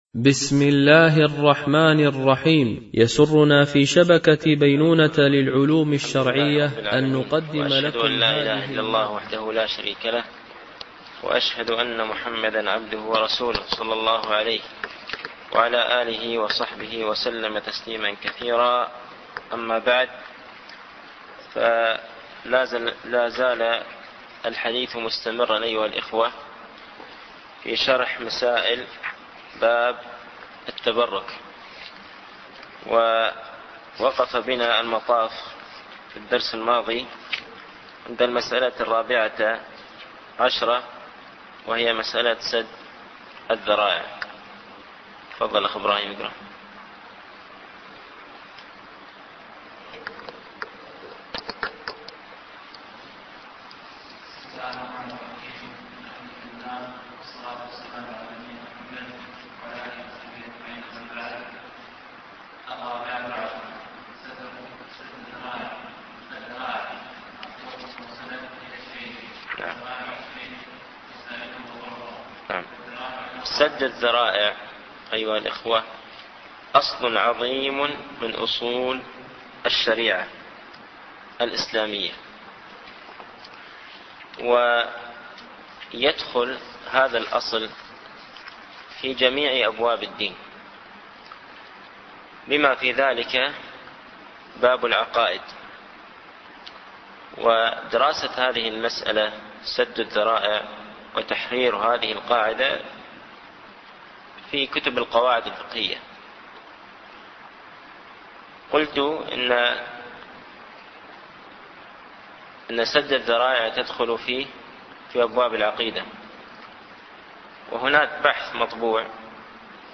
التعليق على القول المفيد على كتاب التوحيد ـ الدرس الخامس و العشرون